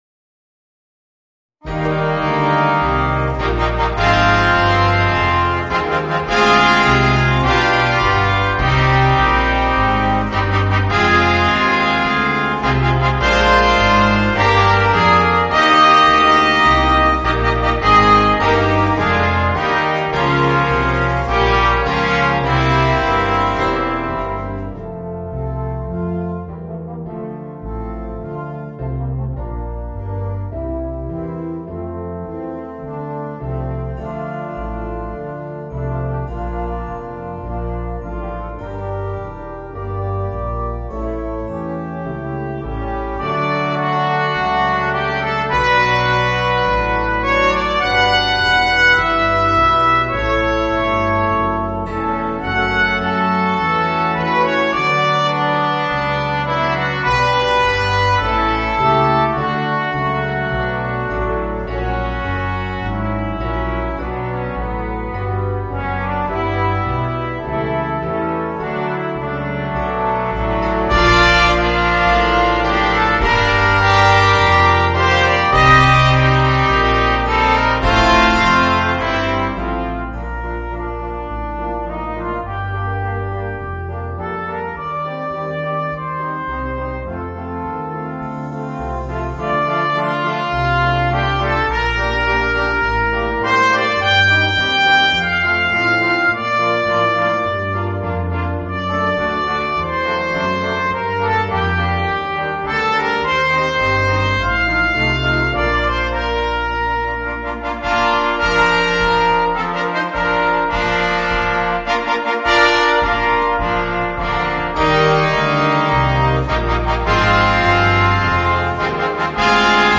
Voicing: 5 - Part Ensemble and Rhythm Section